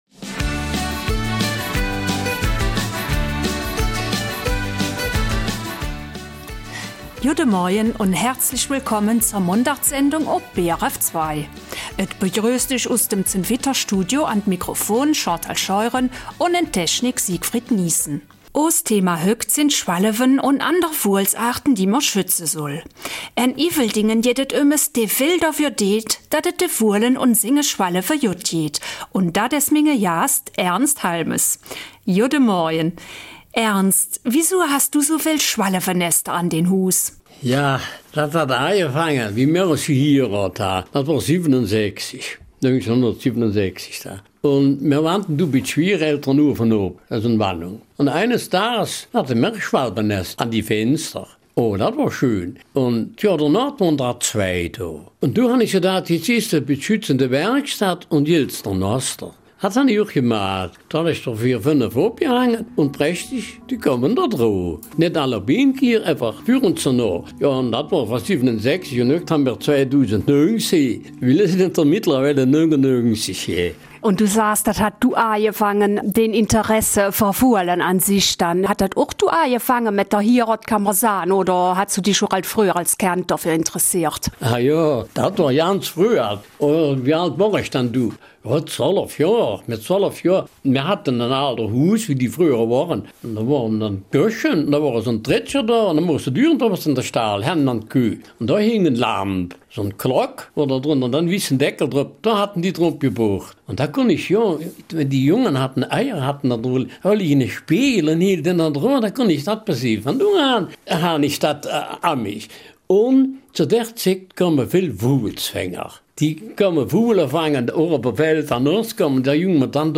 Eifeler Mundart: Schwalbenschutz in Iveldingen